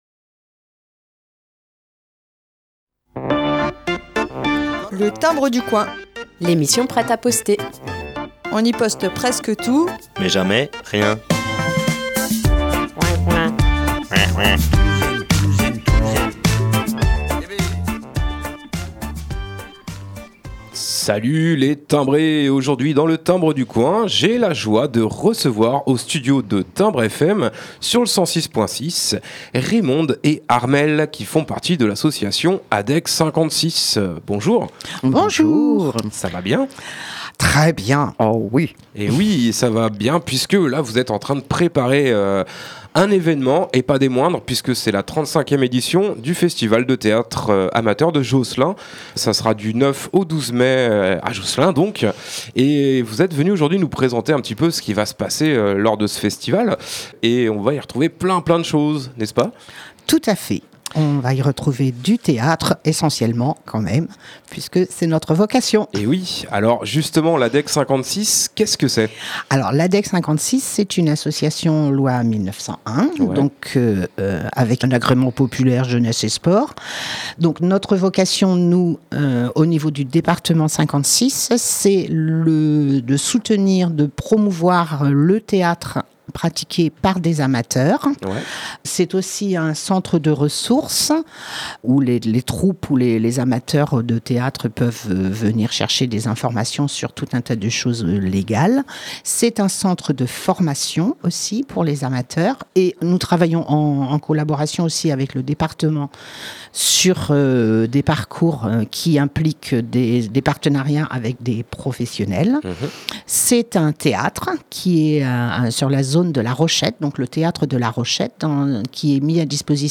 Interview/Invité.e :